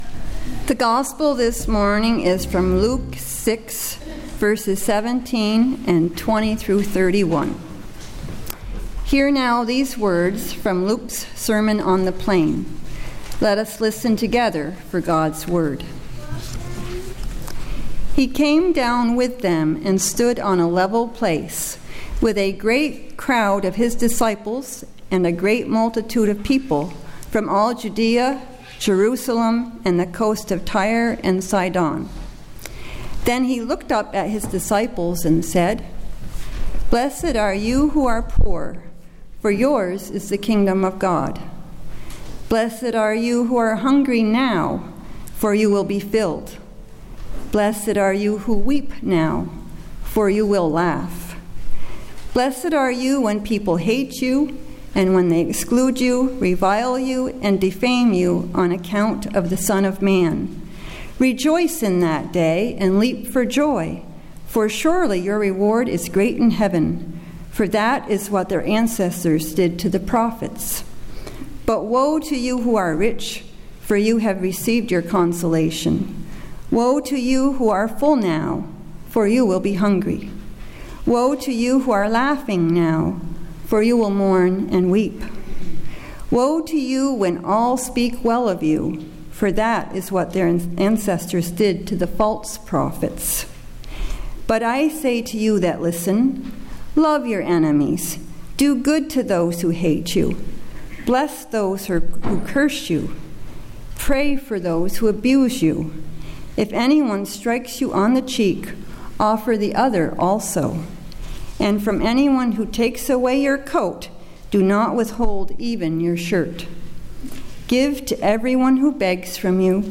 Date: November 3rd, 2013 , (Pentecost 24)
Message Delivered at: The United Church of Underhill (UCC and UMC)